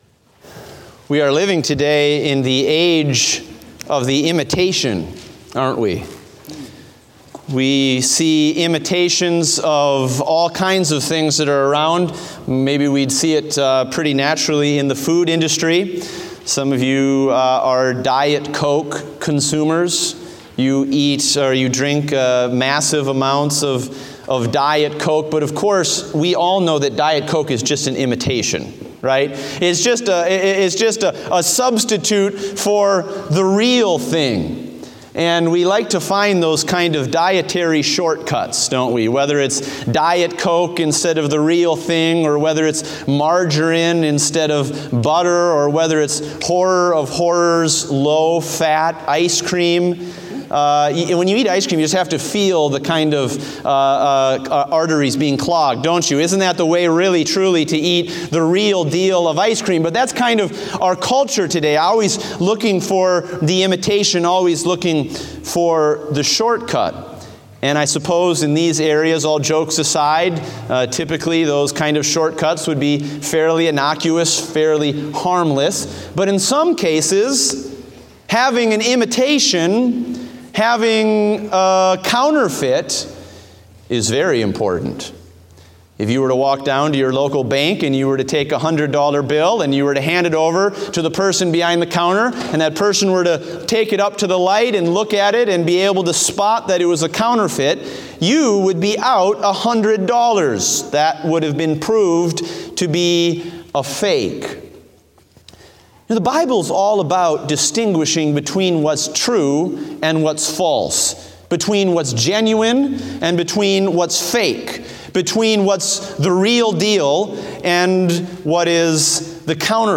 Date: March 26, 2017 (Morning Service)